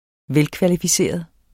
Udtale [ -kvalifiˌseˀʌð ]